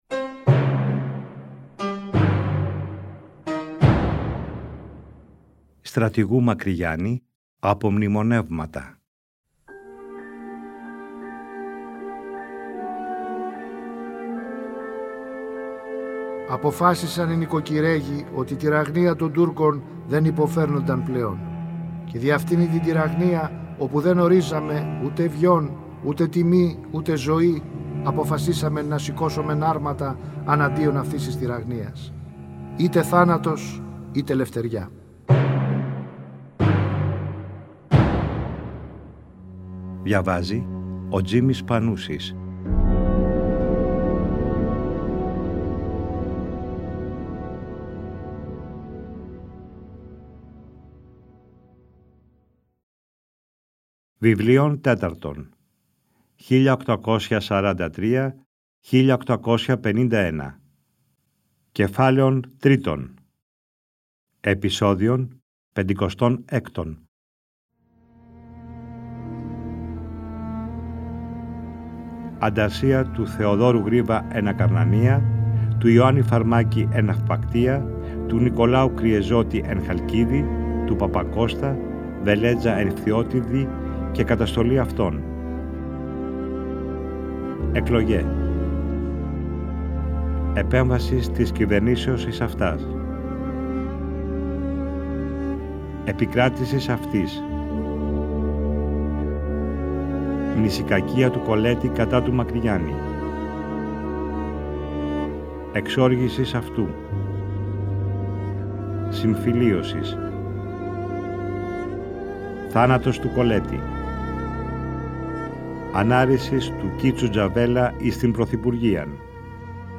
Τον Ιούνιο του 2012 το Τρίτο Πρόγραμμα παρουσίασε για πρώτη φορά μια σειρά 60 ημίωρων επεισοδίων, με τον Τζίμη Πανούση να διαβάζει τον γραπτό λόγο του Μακρυγιάννη, όπως ο ίδιος ο Στρατηγός τον αποτύπωσε στα “Απομνημονεύματα” του. Το ERT εcho σε συνεργασία με το Τρίτο Πρόγραμμα αποκατέστησαν ψηφιακά τα αρχεία.
Ανάγνωση: Τζίμης Πανούσης